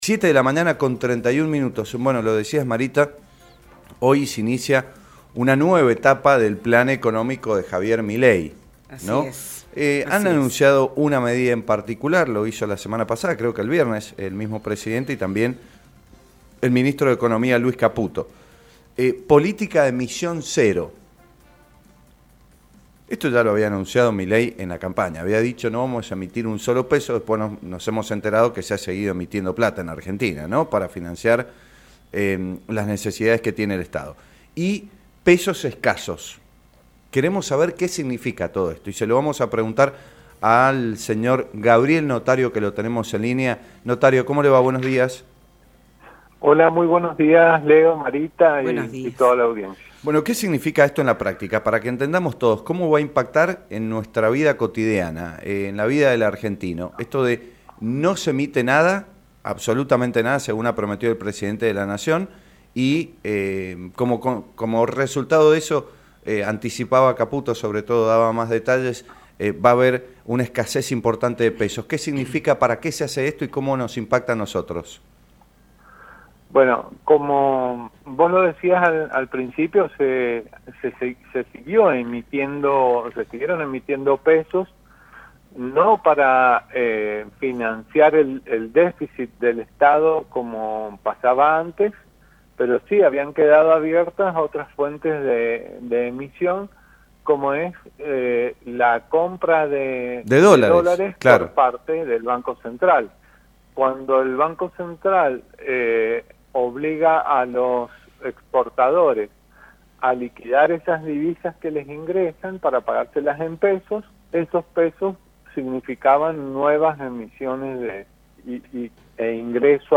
El consultor financiero